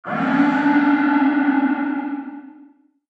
• Качество: 128, Stereo
громкие
без слов
тревожные